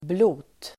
Ladda ner uttalet
Uttal: [blo:t]